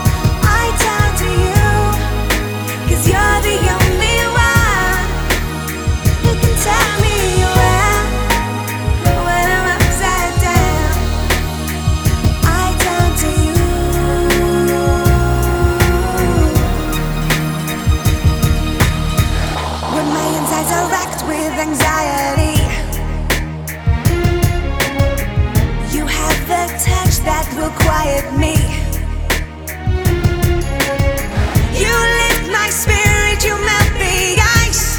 Pop Britpop Dance Electronic
Жанр: Поп музыка / Танцевальные / Электроника